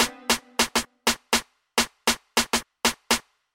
非洲节拍舞蹈打击乐
描述：打击乐，使非洲人在俱乐部里跳舞。
Tag: 135 bpm Ethnic Loops Percussion Loops 612.64 KB wav Key : C